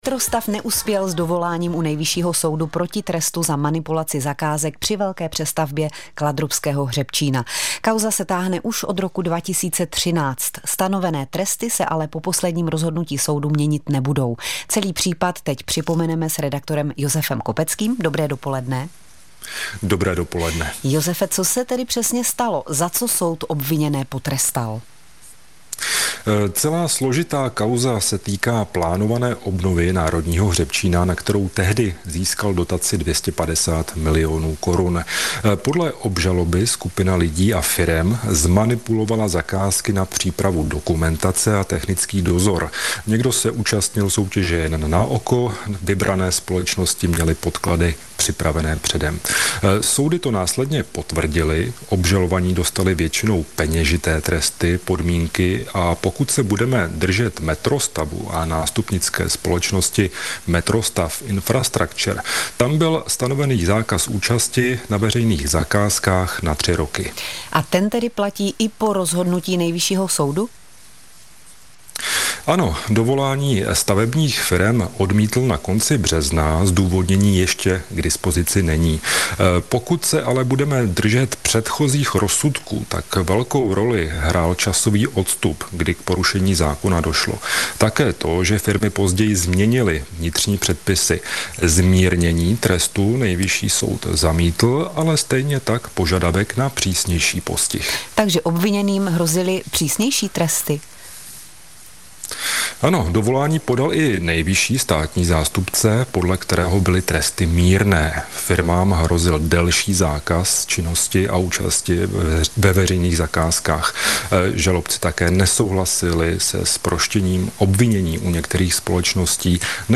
Zprávy pro Pardubický kraj: Nejvyšší soud odmítl dovolání Metrostavu v kauze přestavby kladrubského hřebčína - 10.04.2025